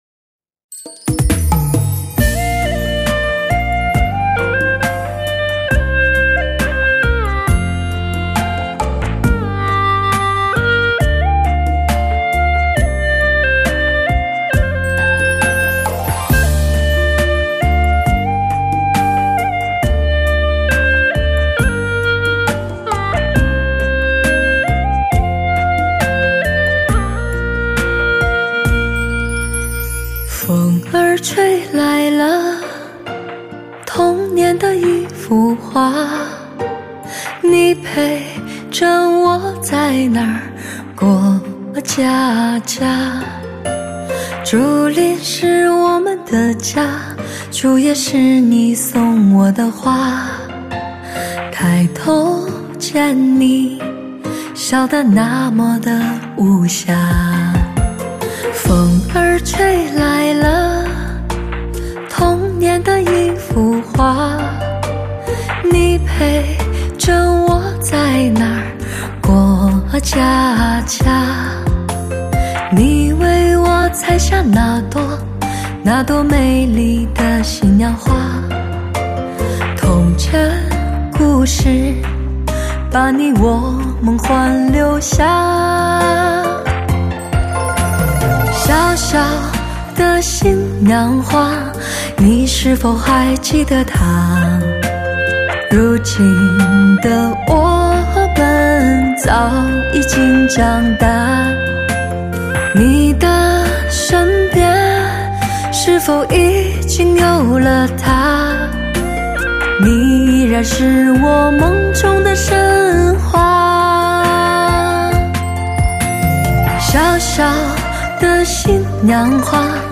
唱片类型：汽车音乐
磁性沙哑的嗓音，柔美动人的歌曲，欣赏中。